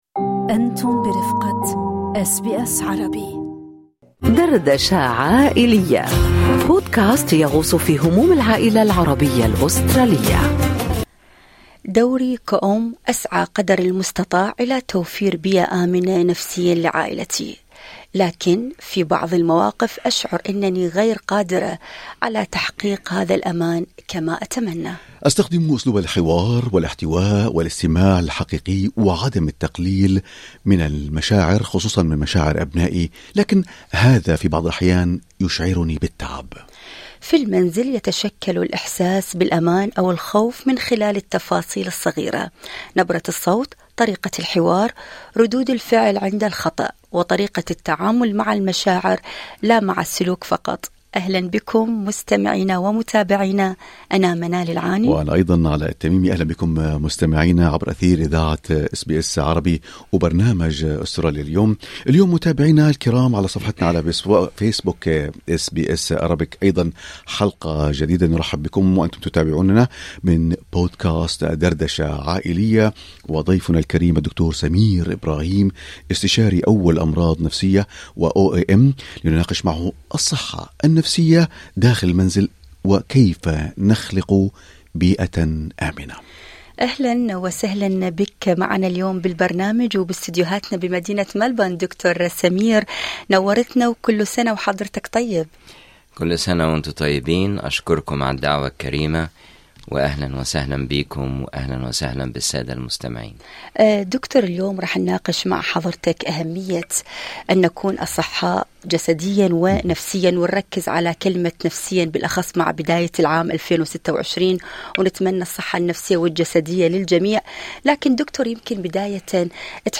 هل شعرتم بعدم استطاعتكم توفير الآمان لأبنائكم في المنزل؟ طبيب نفسي يقدم الحلول؟